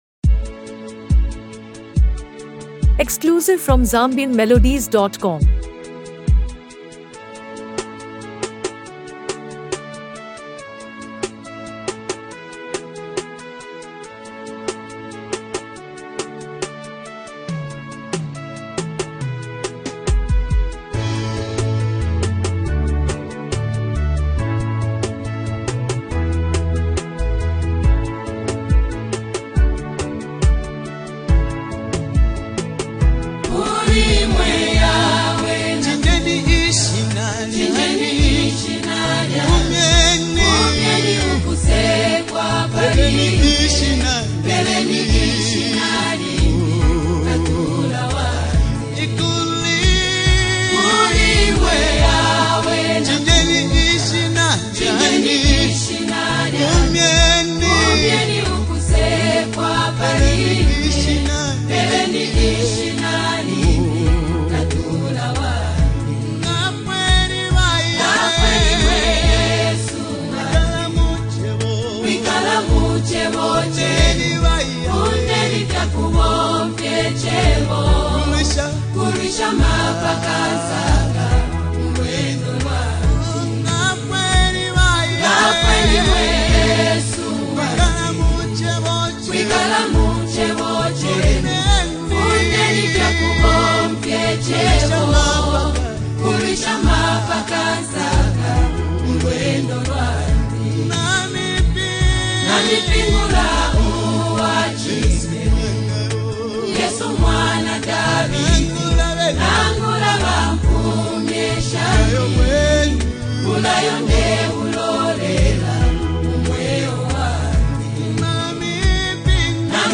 contemporary gospel